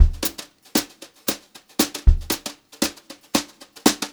116JZBEAT1-L.wav